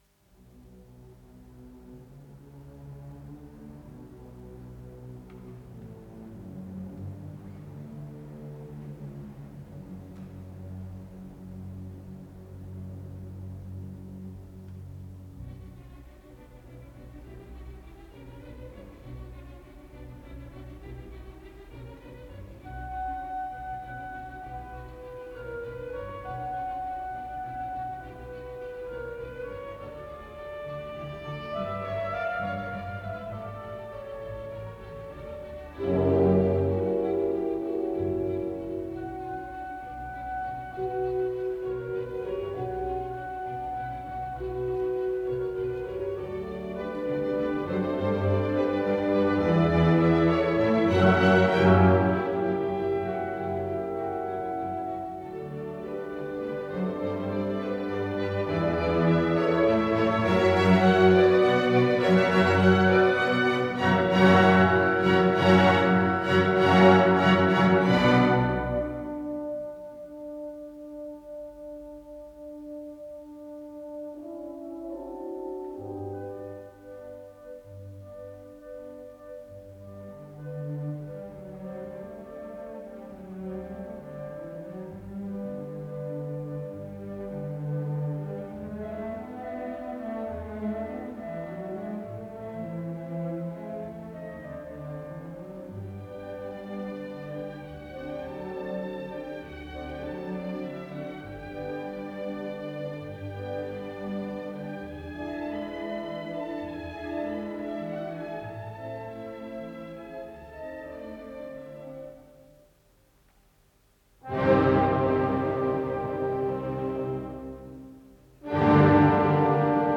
Исполнитель: Академический симфонический оркестр Ленинградской государственной филармонии им. Дмитрия Шостакович
Си минор